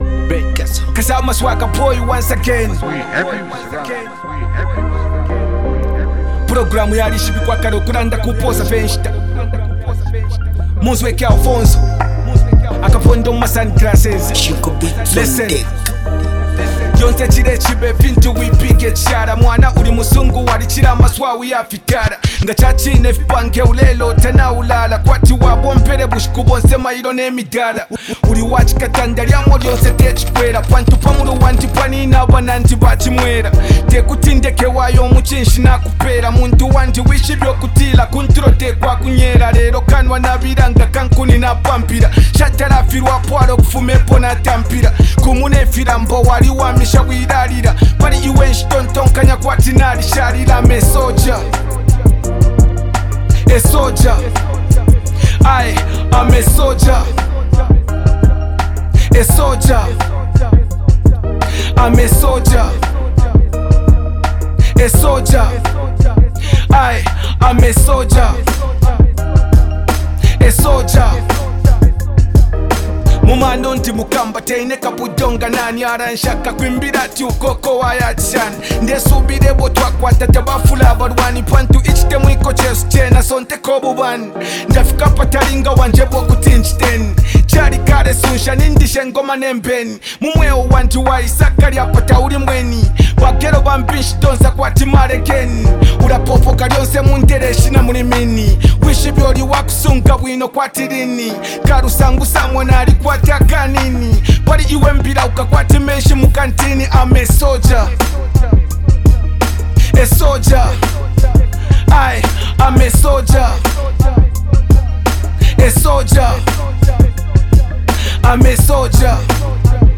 Zambian rap star
hip-hop song